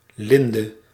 Ääntäminen
Synonyymit lindeboom Ääntäminen Haettu sana löytyi näillä lähdekielillä: hollanti Käännös Ääninäyte Substantiivit 1. linden US Suku: m .